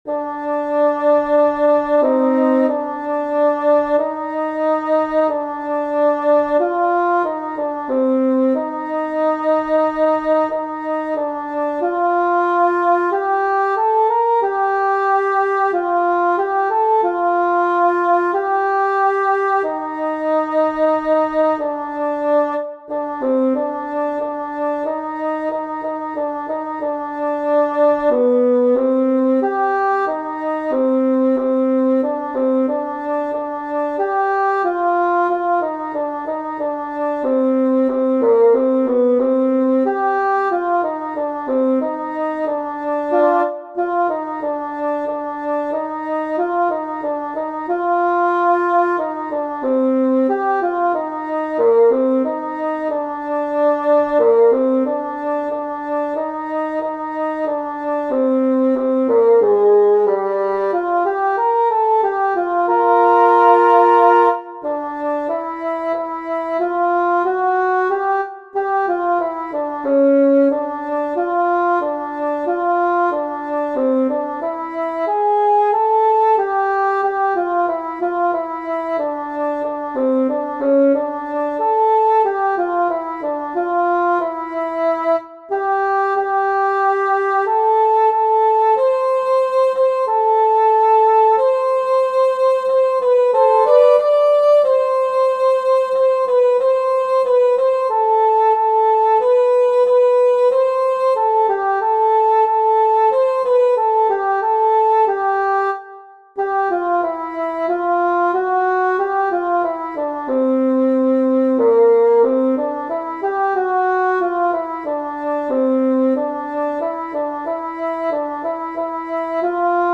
non_nobis_domine-alto.mp3